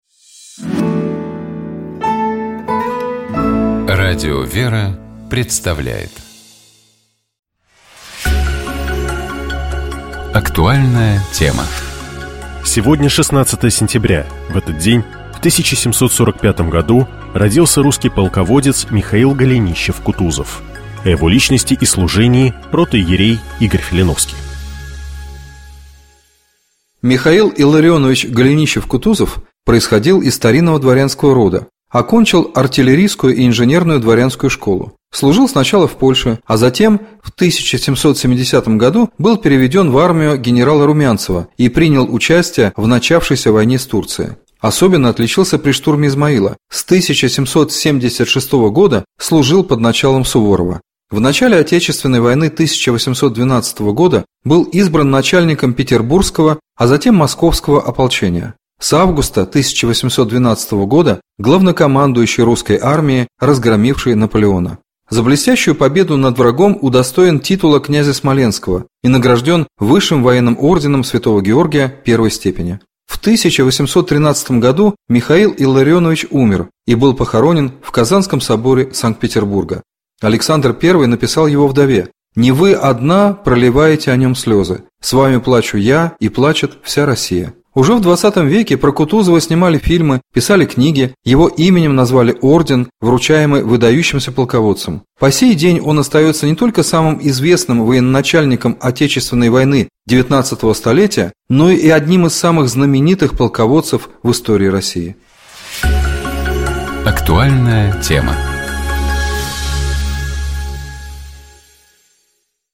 Псалом 17. Богослужебные чтения Скачать Поделиться Какой язык наиболее подходит для того, чтобы говорить о Боге?